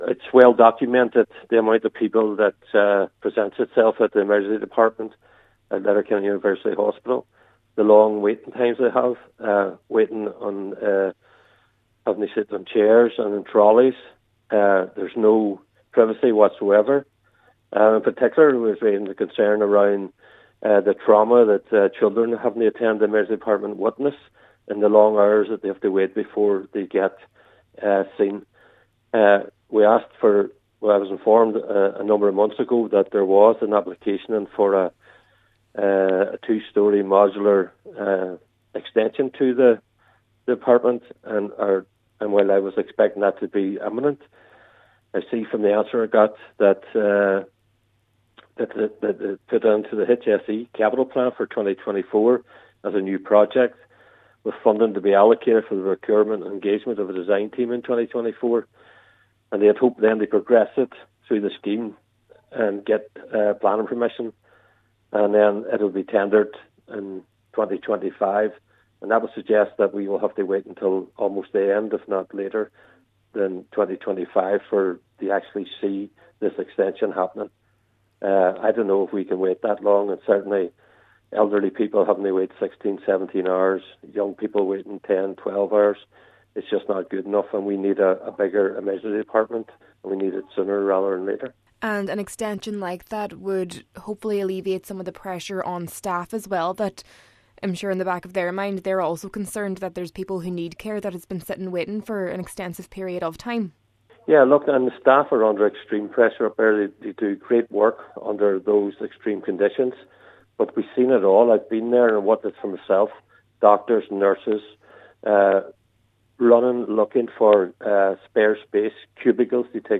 Councillor Gerry McMonagle, a member of the Regional Health Forum West says the hospital is crying out for additional capacity to treat people presenting at the ED: